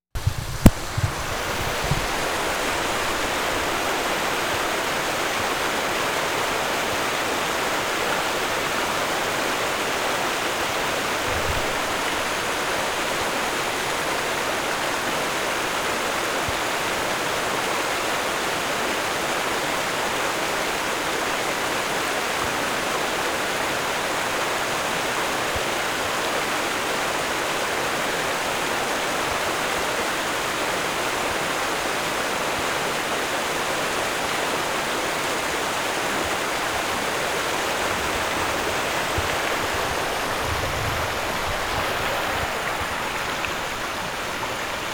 HORSESHOE BAY, SURROUNDING AREA NOV. 3, 1991
8. good stereo, mic noise throughout